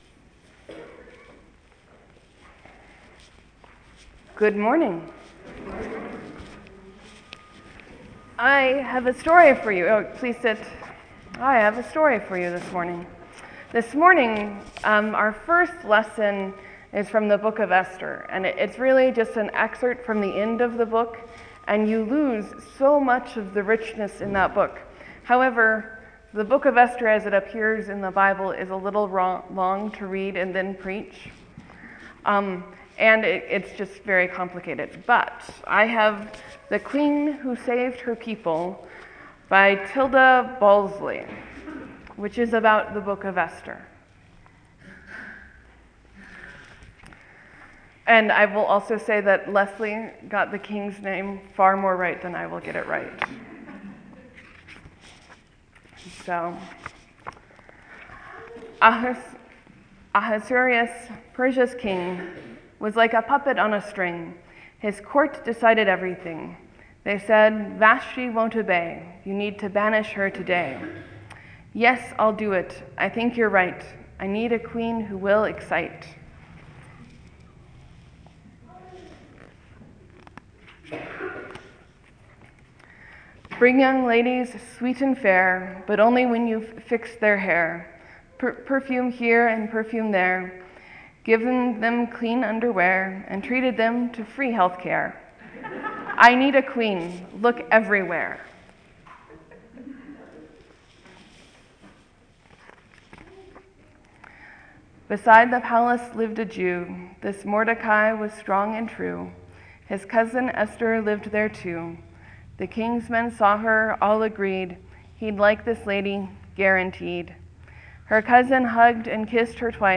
Sermon: Jesus gets all extreme today. How do we deal with that?